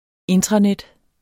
Udtale [ ˈentʁɑ- ]